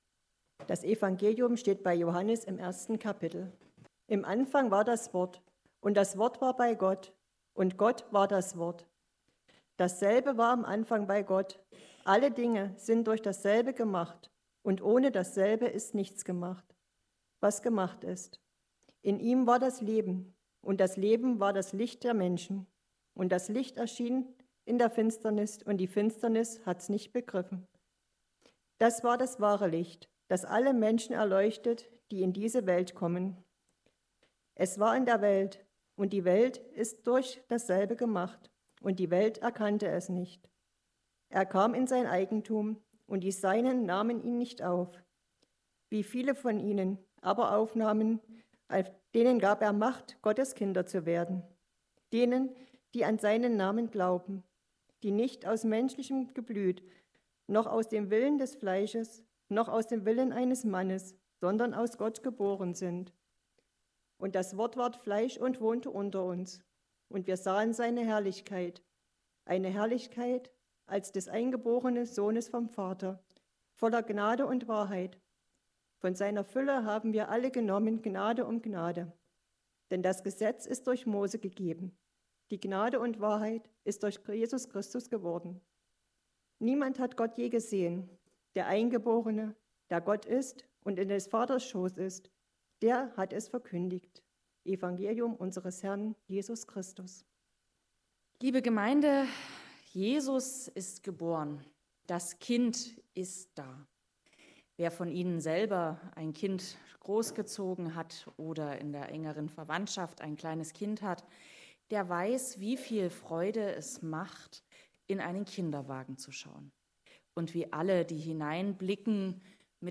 1-18 Gottesdienstart: Predigtgottesdienst Obercrinitz Jedes Kind ist die fleischgewordene Liebe seine Eltern.